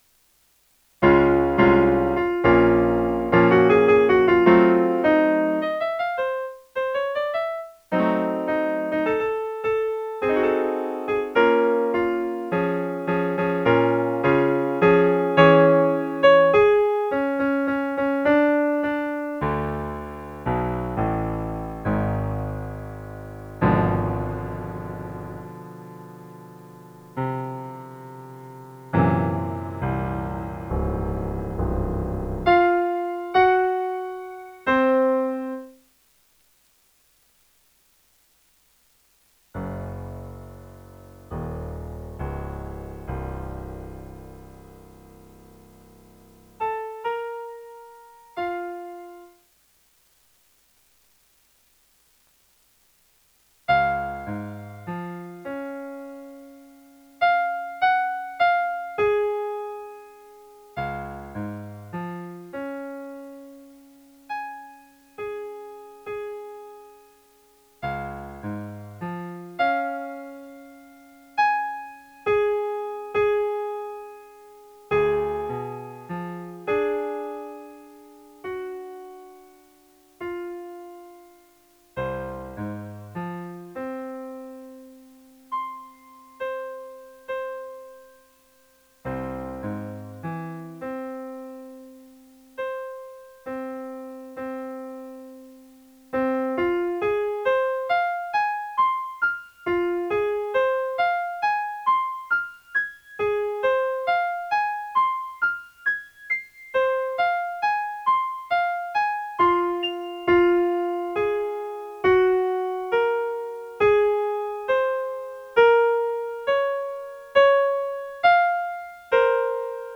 ''Ich sank verweint in sanften schlummer'' - 피아노 반주